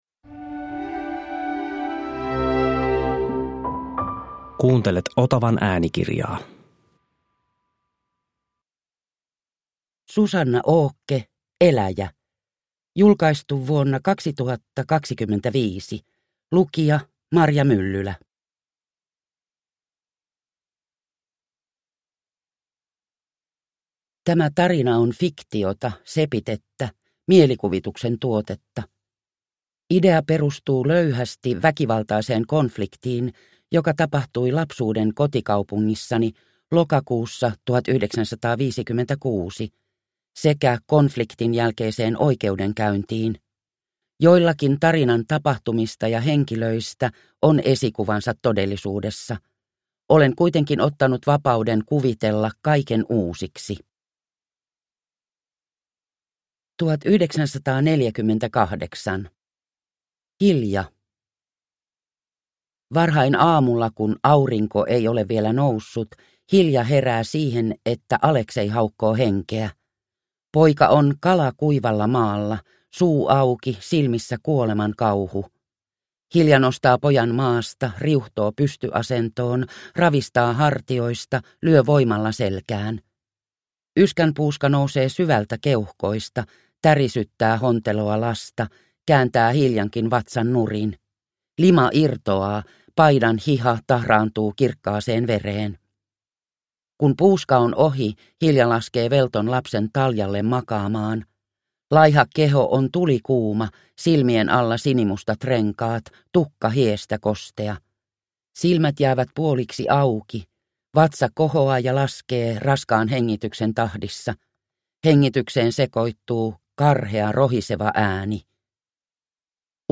Eläjä (ljudbok) av Susanna Åke